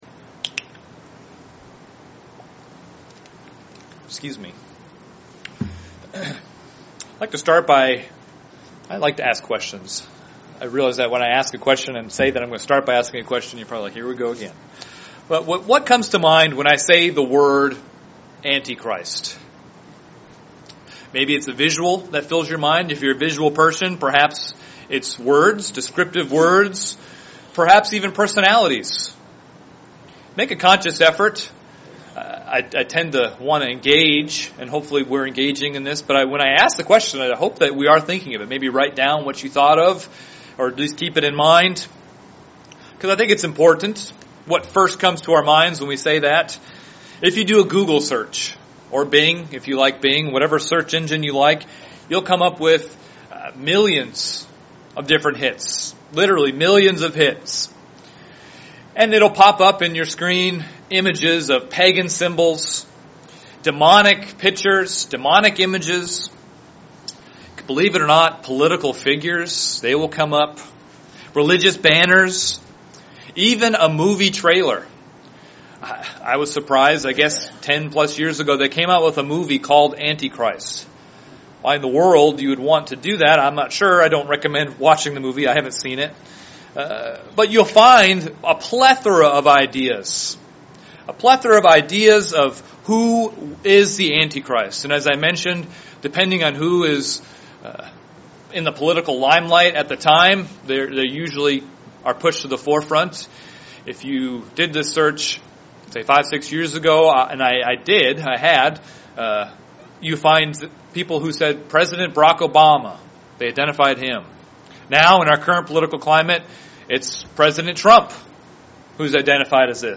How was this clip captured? Given in Lansing, MI